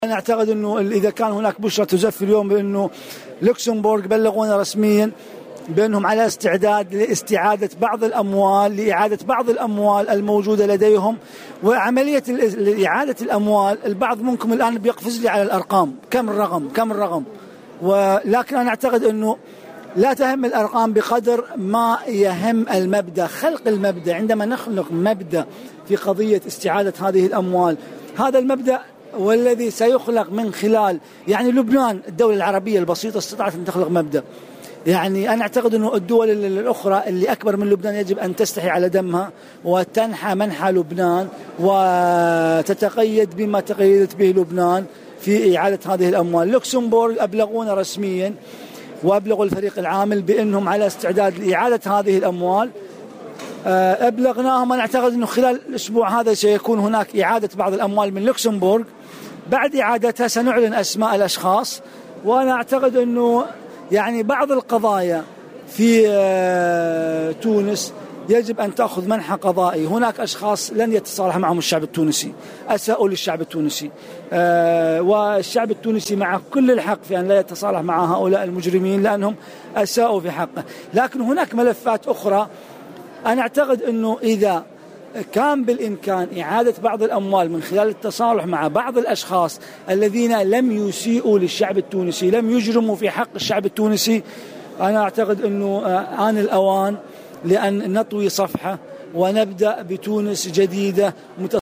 وأضاف في تصريح إعلامي اليوم الأربعاء على هامش المنتدى العربي لاسترداد الأموال المنهوبة بالحمامات أن الأمم المتحدة ستعمل على استعادة تونس لأموالها المهربة في الخارج.